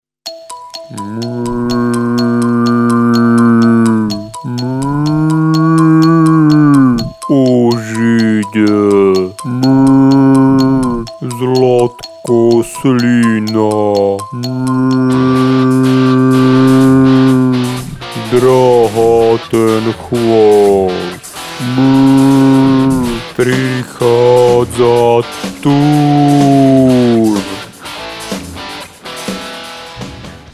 ID jingel 1